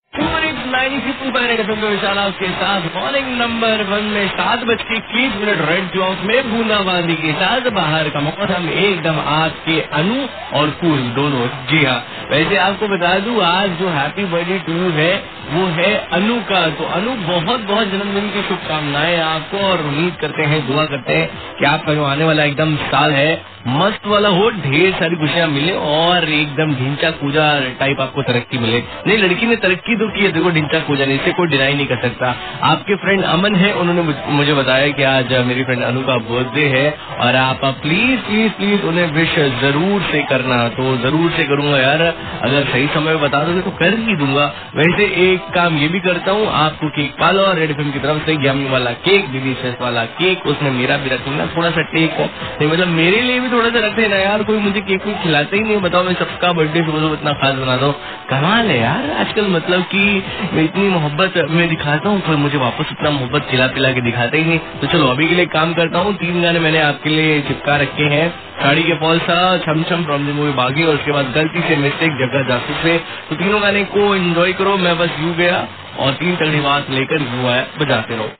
RJ WISHES HAPPY BDAY